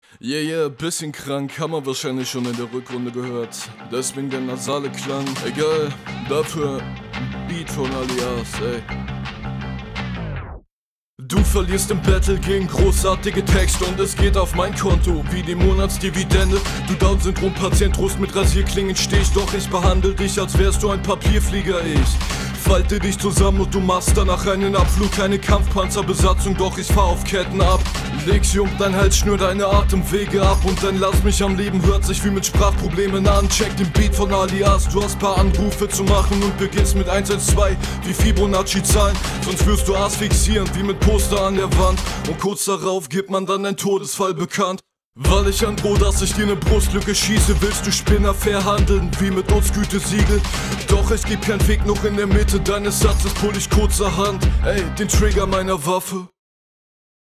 Nicer Beat. Sound wie immer gut.
Der Beat ist geil und du kommst geil darauf.